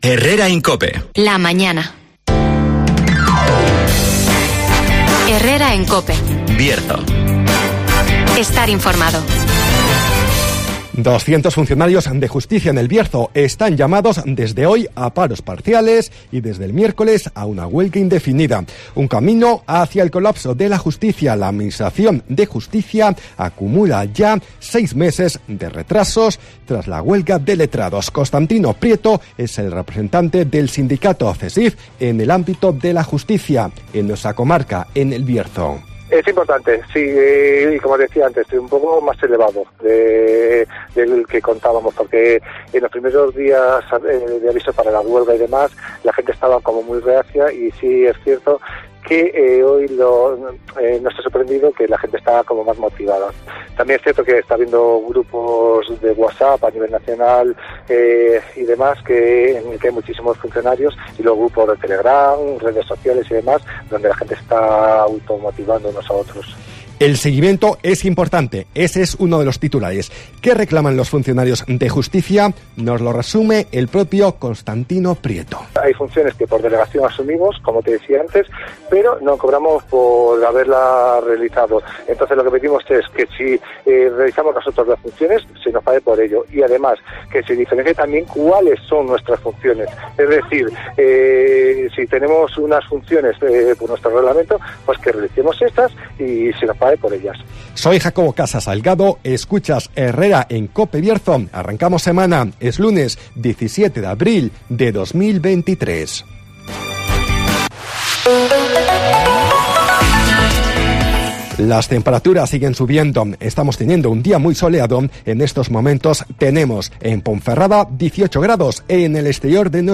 Resumen de las noticias
Deportes -Todo preparado para una nueva edición de la Feria del Libro de Ponferrada (Entrevista